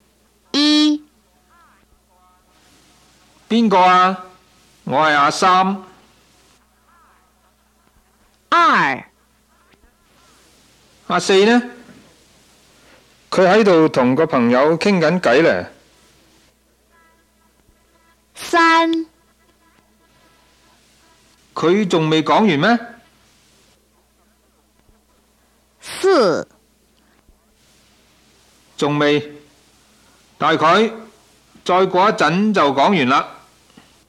In each of the audio files below, the speaker will say the following, at least how they would say the same thing in their dialect.
6. Cantonese—guǎngdōnghuà (Yue Group; Guangdong Province)
06-guangdong-hua.m4a